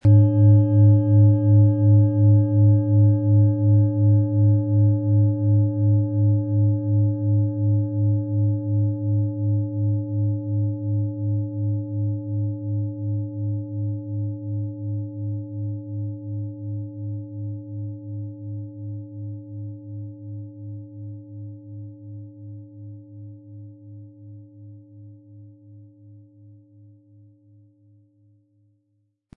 Planetenschale® Intuition stärken & Zentriert fühlen mit Neptun & Saturn, Ø 23,3 cm, 1200-1300 Gramm inkl. Klöppel
• Mittlerer Ton: Saturn
• Höchster Ton: OM-Ton
Im Sound-Player - Jetzt reinhören können Sie den Original-Ton genau dieser Schale anhören.
PlanetentöneNeptun & Saturn & OM-Ton (Höchster Ton)
MaterialBronze